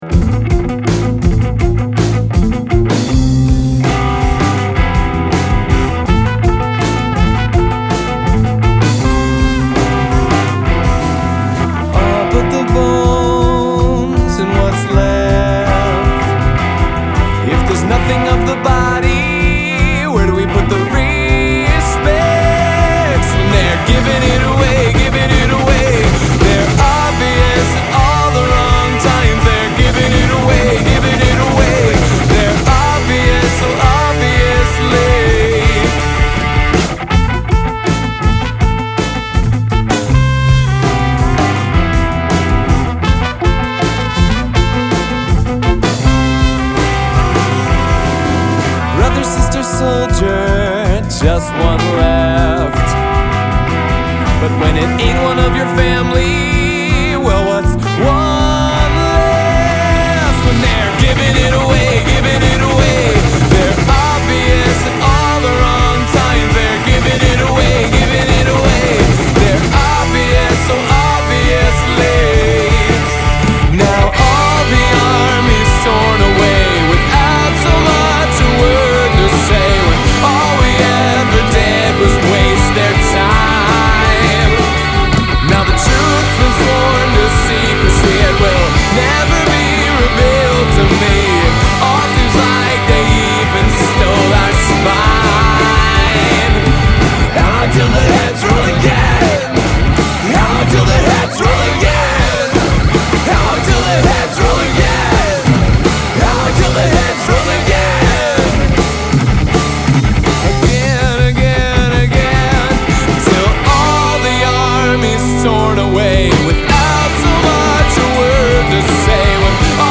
the slamming alter-ego rock mutation
trumpet-commanded battle march